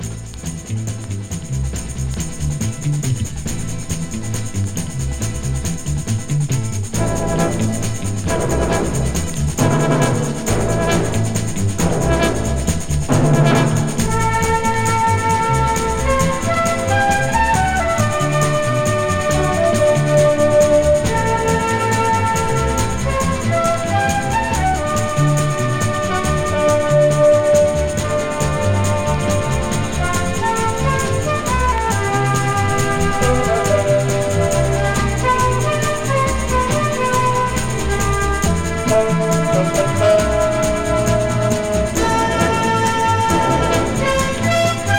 盤自体に起因するプチプチ音有り